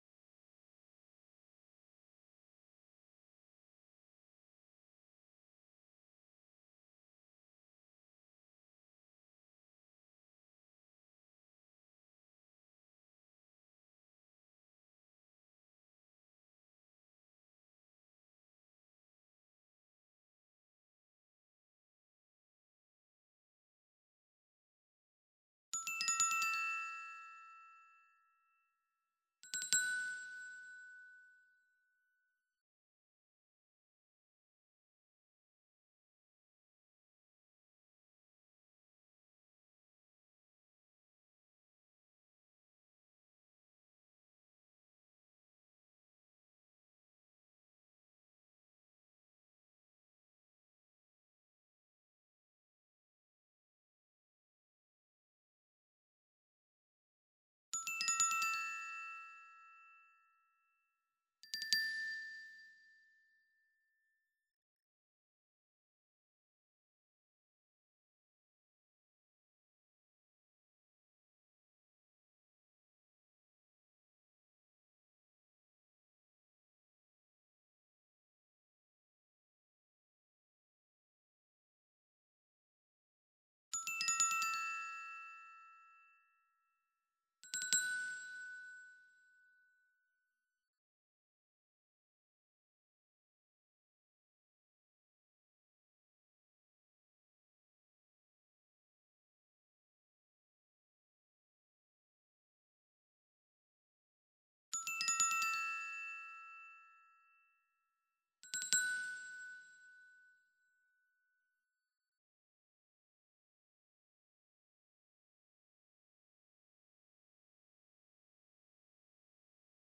GLOCKENSPIEL